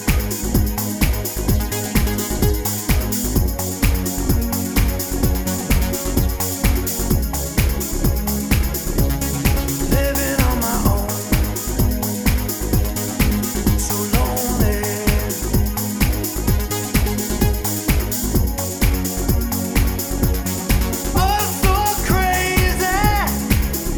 Single Mix One Semitone Down Pop (1980s) 3:29 Buy £1.50